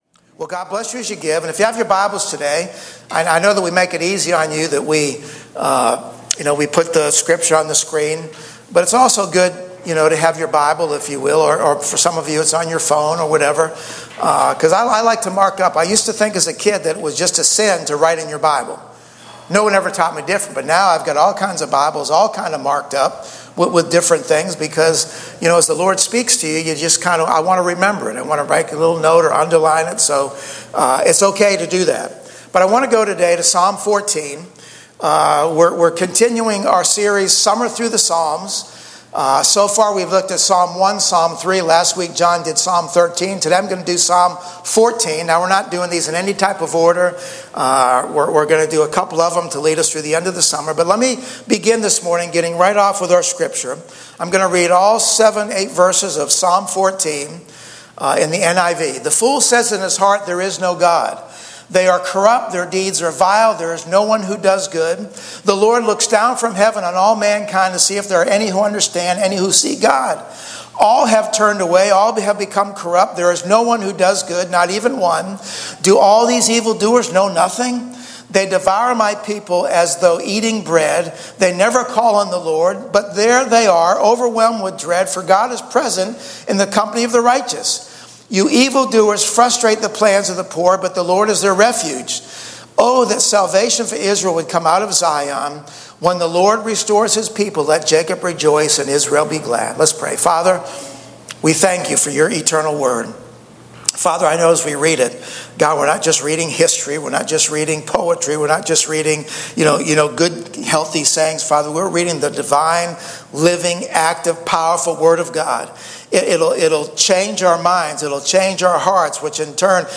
Online-Church-July-28-AUDIO.mp3